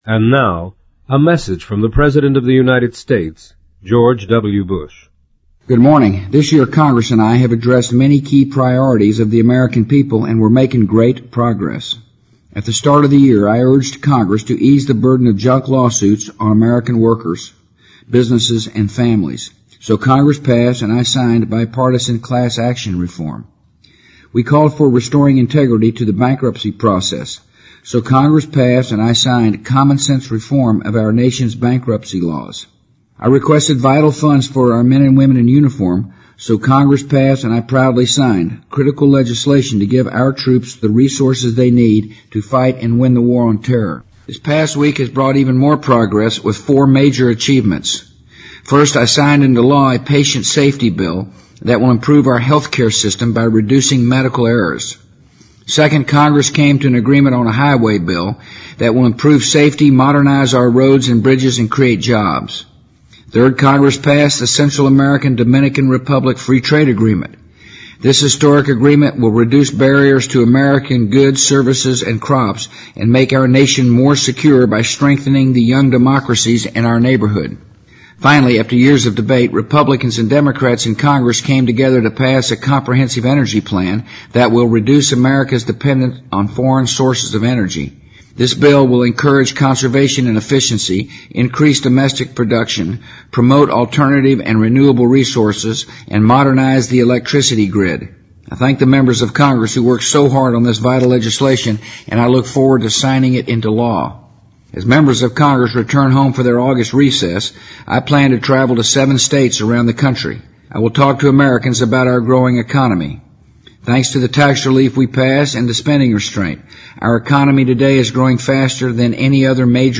【美国总统电台演说】2005-07-30 听力文件下载—在线英语听力室